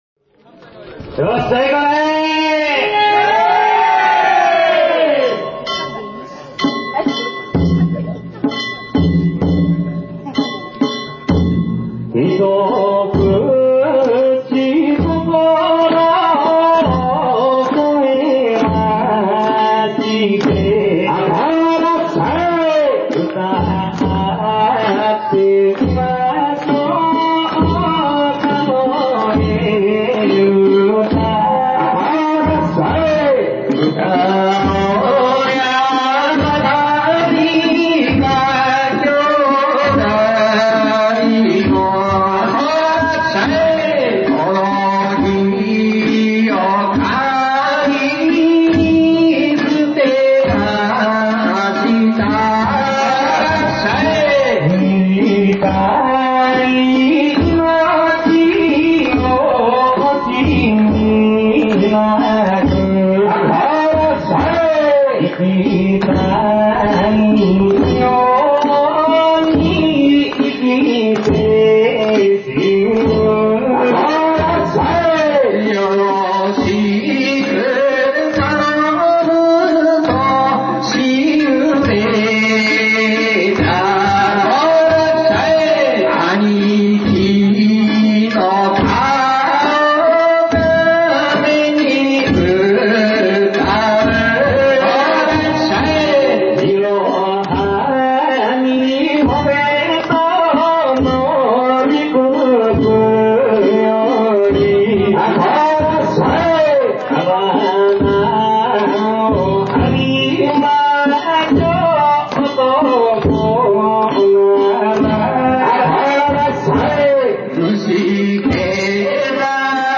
平成２８年２月２１日、河南町の今堂地車お披露目曳行を見に行ってきました。
なんと白木の曳き歌が始まりましたよ♪
そして、なんとなんと一節ごとに白木と今堂が交代で歌っていますよ♪